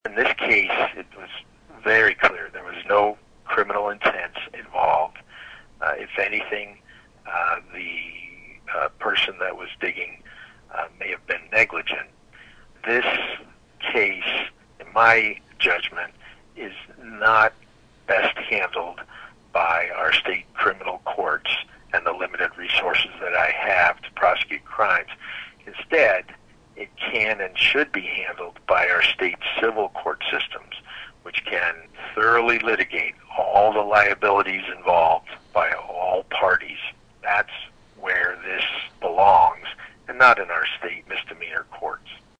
Prosecutor Denis Tracy declined to pursue the case finding that there was no criminal intent on the part of the farmer.  You can listen to Tracy explain his decision below.